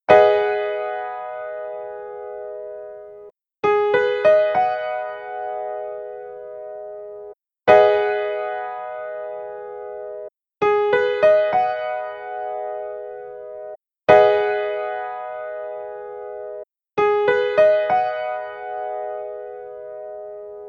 Abm7 Piano Chord G#m7
Abm7-Piano-Chord-2.mp3